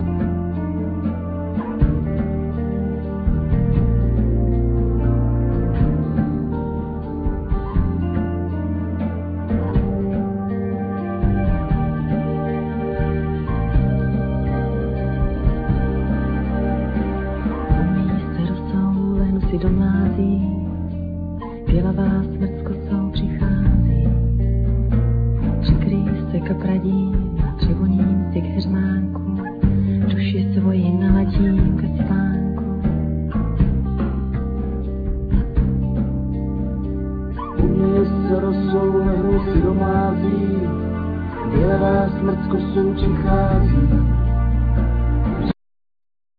Guitar,Vocal
Bass
Drums,Percussion,Sound Wheel
Trumpet
Keyboards
Children Choir